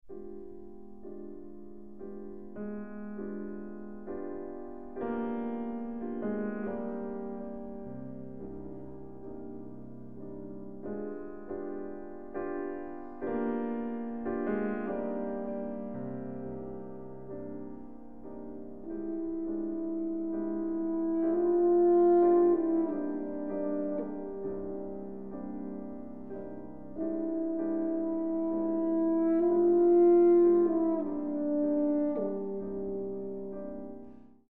Horn
Piano
Iwaki Auditorium, ABC Southbank, Melbourne